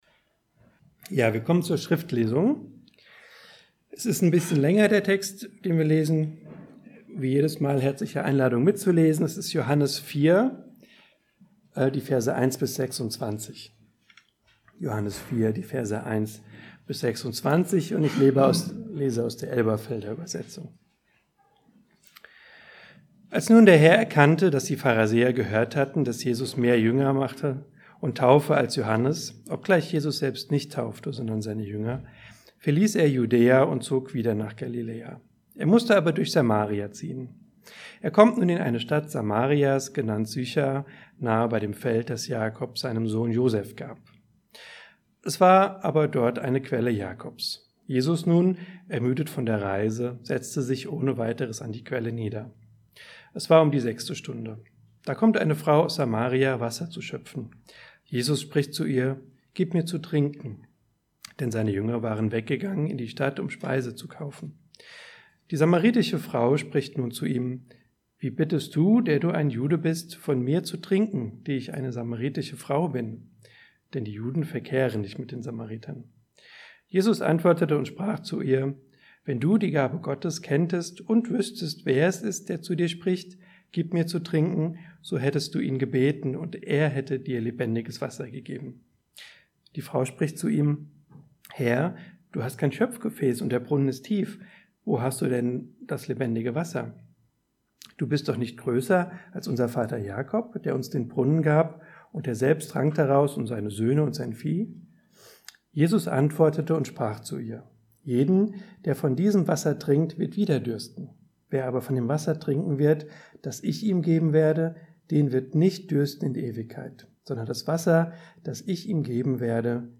Johannes 1, 1-18 Download file Veröffentlicht unter Predigt Die Geduld des Gerechten Veröffentlicht am 18.